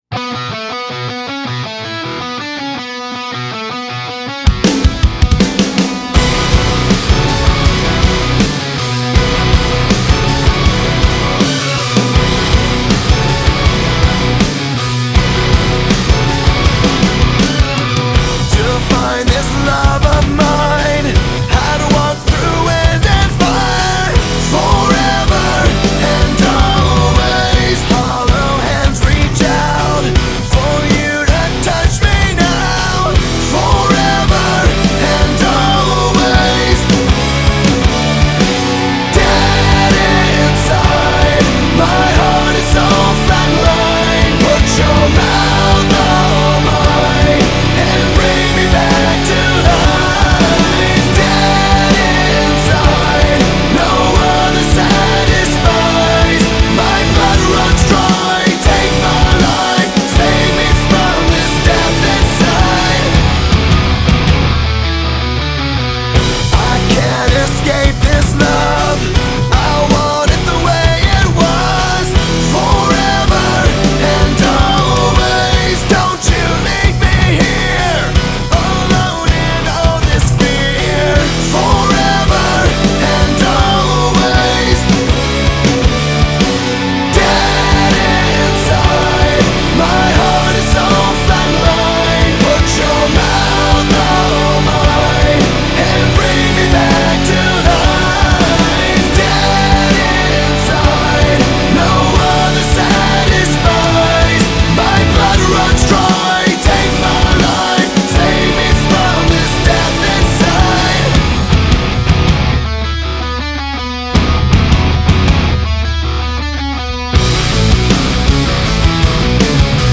Hard Rock
Alternative Rock